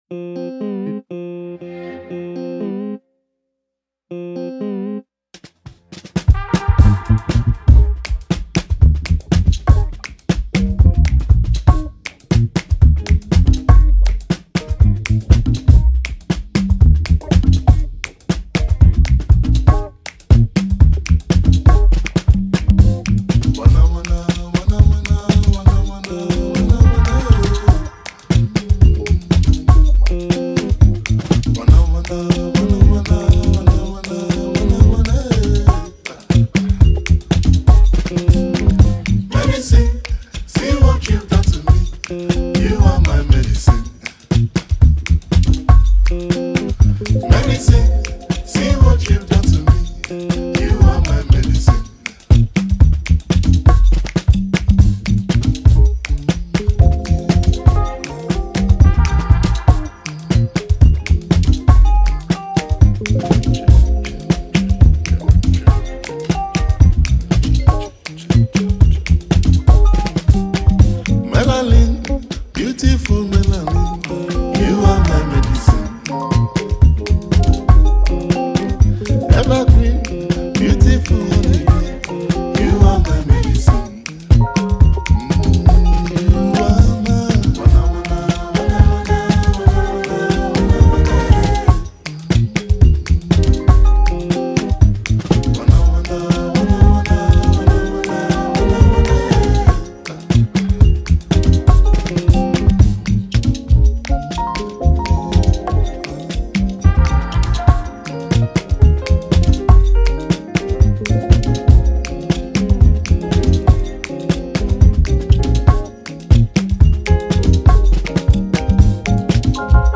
Nigerian Afro-Highlife and Alternative Sound Music Duo